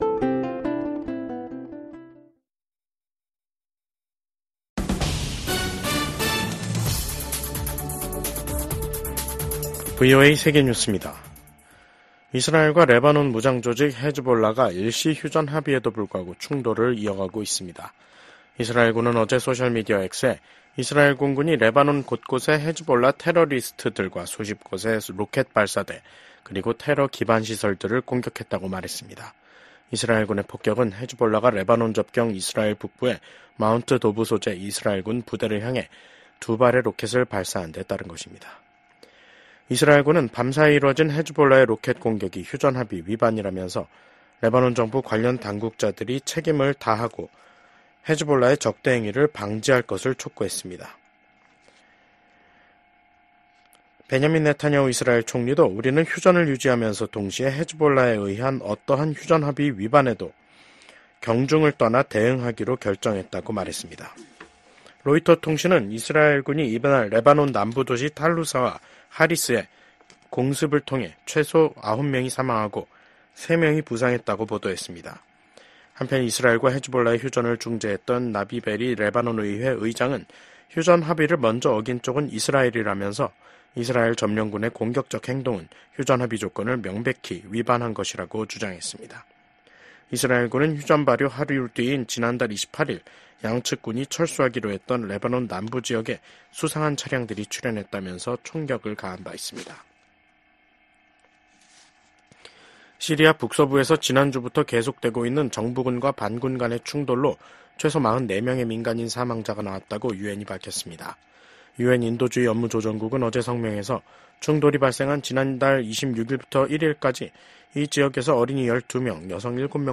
VOA 한국어 간판 뉴스 프로그램 '뉴스 투데이', 2024년 12월 3일 2부 방송입니다. 미국 국무부는 북러 간 군사협력을 매우 우려한다며 북한군 파병 등에 대응해 우크라이나 방위력 강화를 위한 조치를 취할 것이라고 밝혔습니다. 러시아에 파병된 북한군이 아직 최전선에서 공격 작전에 참여하지 않고 있다고 미국 국방부가 밝혔습니다. 북한이 연말에 노동당 중앙위원회 전원회의를 열어 한 해를 결산합니다.